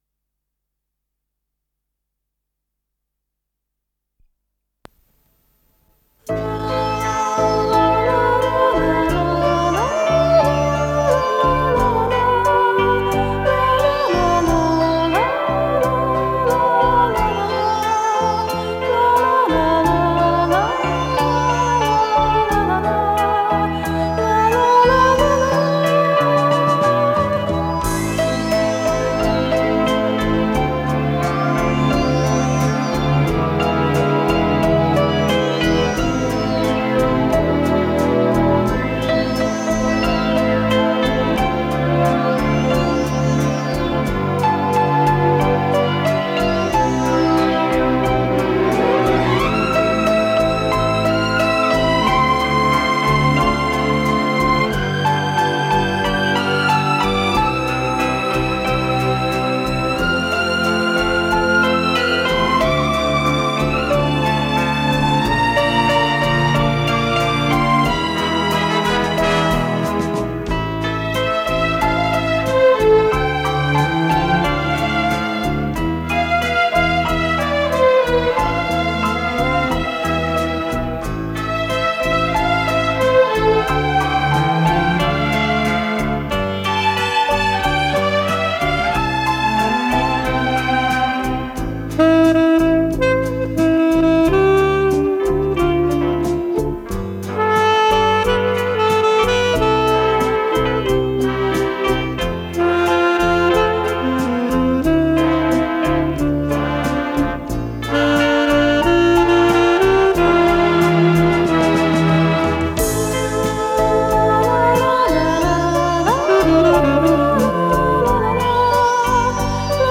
с профессиональной магнитной ленты
Вокализ
ВариантДубль моно